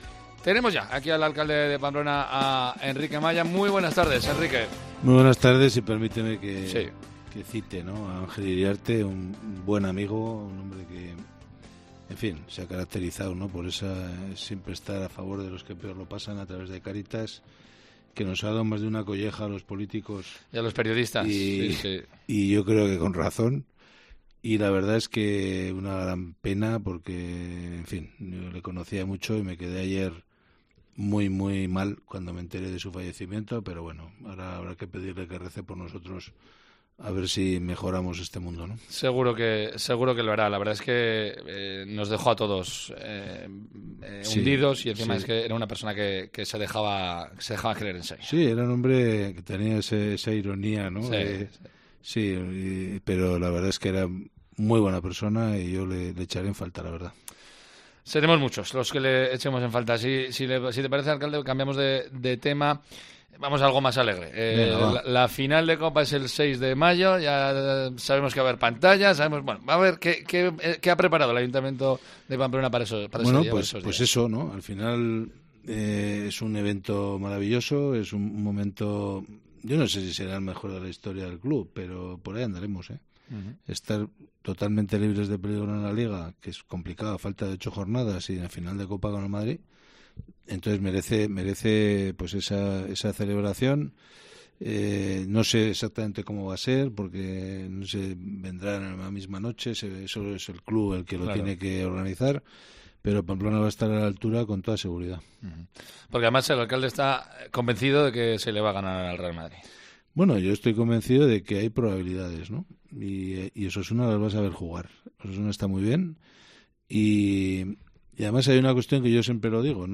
AUDIO: Enrique Maya responde a las preguntas de los oyentes en Cope Navarra en el lunes 24 de abril.